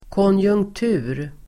Ladda ner uttalet
Uttal: [kånjung(k)t'u:r]